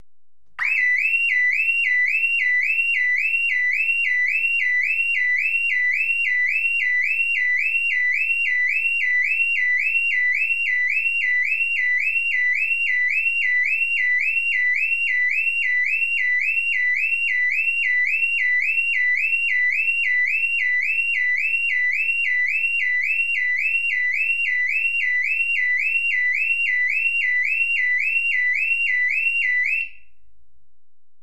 Звуки охранной сигнализации
громкая и шумная автомобильная сигнализация